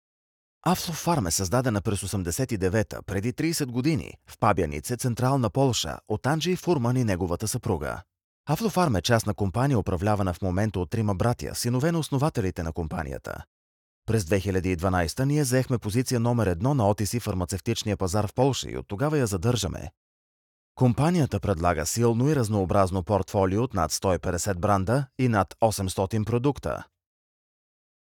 Commercieel, Natuurlijk, Stoer, Warm, Zakelijk
Corporate